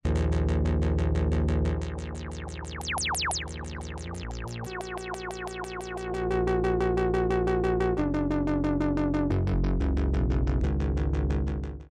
demo HEAR VCF cutoff resonance